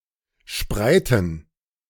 Spreite (German: [ˈʃpʁaɪ̯tə] ), meaning leaf-blade in German (or spreiten (pronounced [ˈʃpʁaɪ̯tn̩]
De-spreiten.ogg.mp3